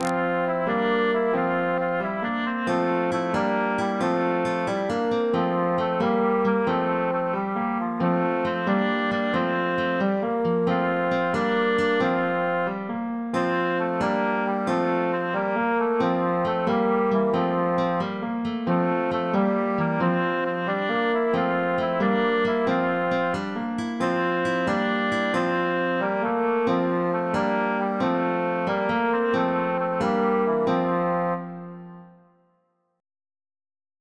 The 13th-century English round "Sumer is icumen in", written in an early form of mensural notation and in the 6